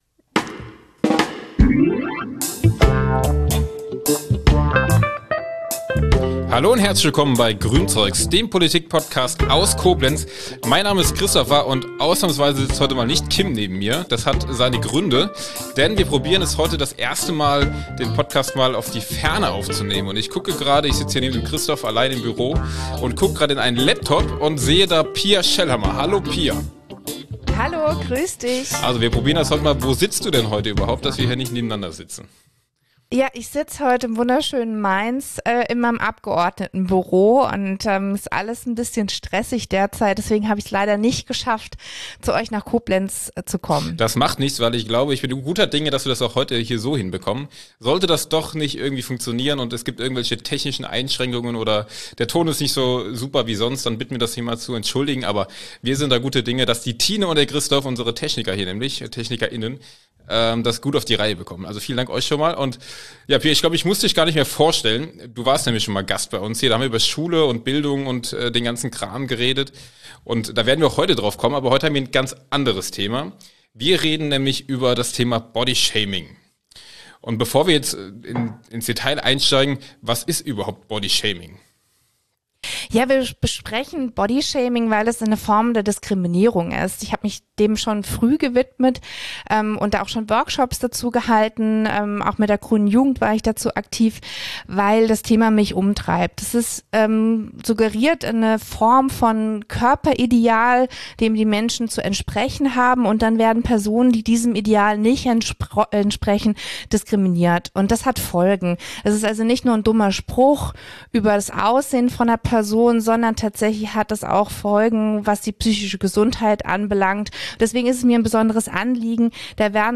Diesen diskriminierenden Angriff nehmen die Koblenzer GRÜNEN zum Anlass, in der aktuellen Ausgabe ihres Podcasts GRÜNZEUGS über Bodyshaming und dessen Folgen zu sprechen. Zu Gast ist Pia Schellhammer, Mitglied des rheinland-pfälzischen Landtags.